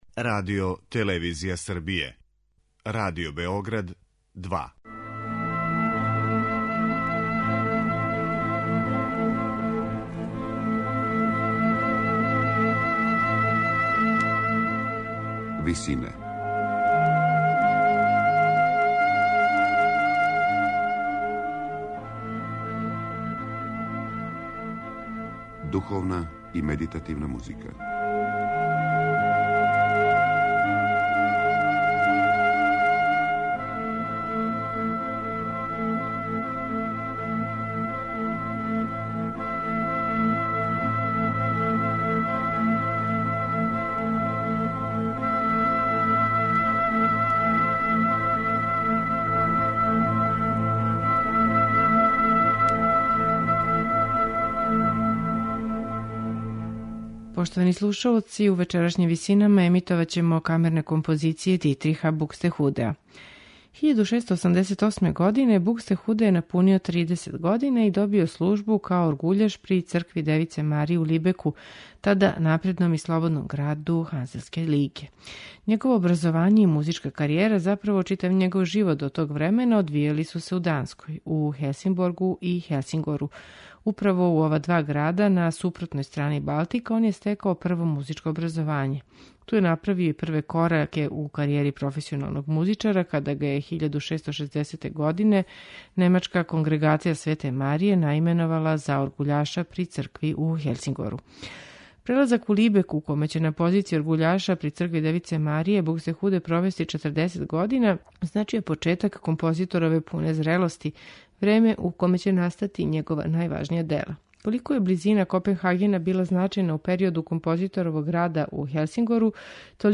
Емитујемо Д Бусеове кантате
баритон
сопран
попречна флаута
виолине
обоа
виола да гамба
теорба
чембало